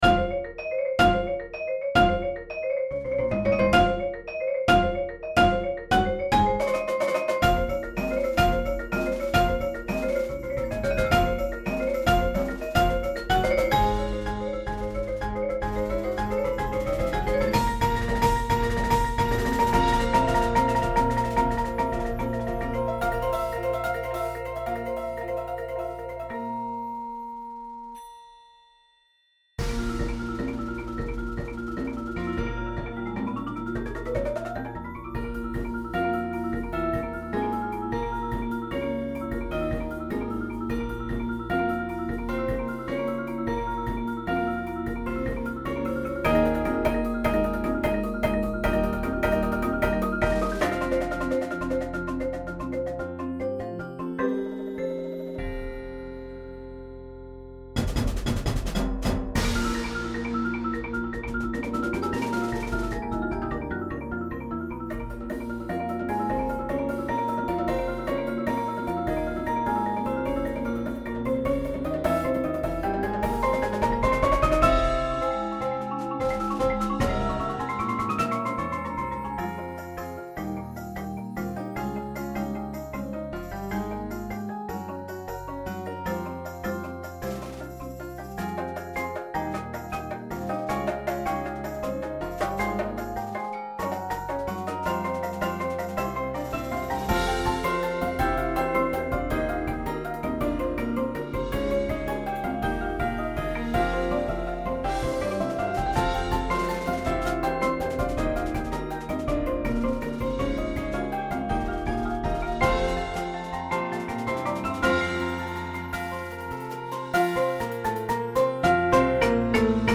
• Bells
• Chimes Xylophone
• 4-6 Marimbas Piano/Synth
• Bass Guitar
• 5 Aux. Percussion